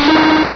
pokeemerald / sound / direct_sound_samples / cries / gligar.aif
-Replaced the Gen. 1 to 3 cries with BW2 rips.